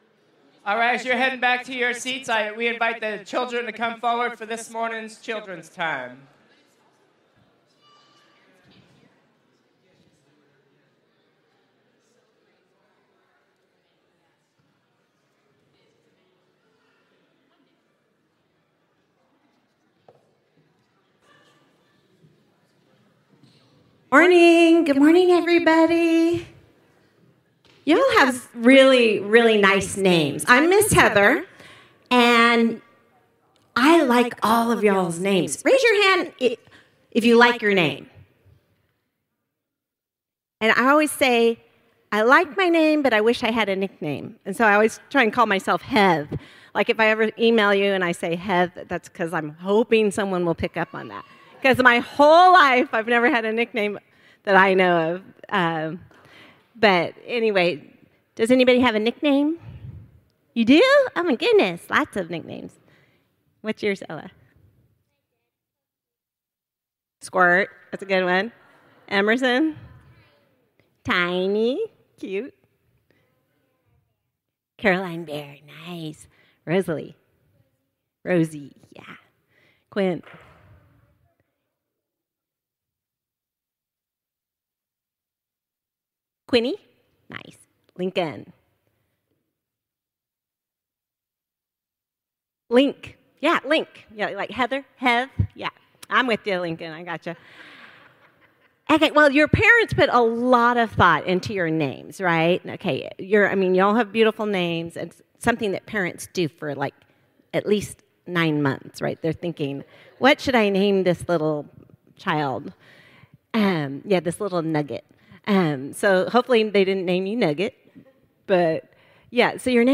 Children’s Time